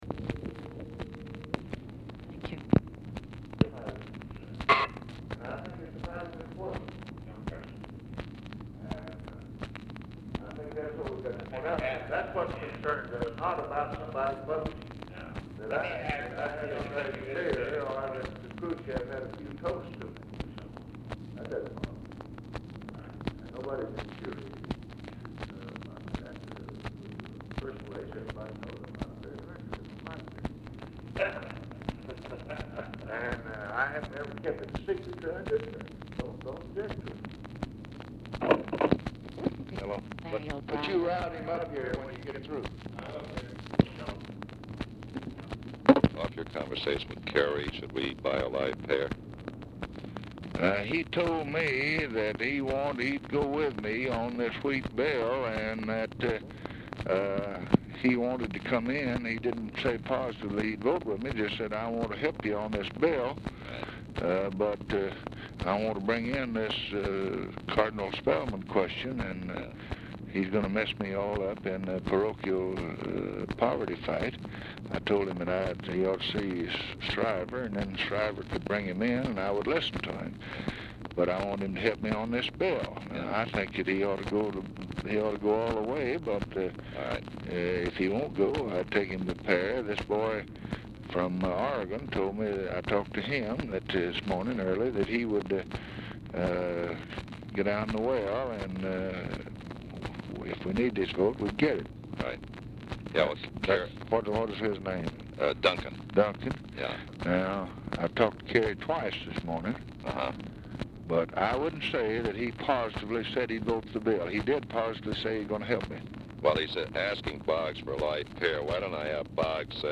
Telephone conversation # 2906, sound recording, LBJ and LARRY O'BRIEN, 4/8/1964, 11:40AM | Discover LBJ
OFFICE CONVERSATION PRECEDES CALL
Format Dictation belt
Oval Office or unknown location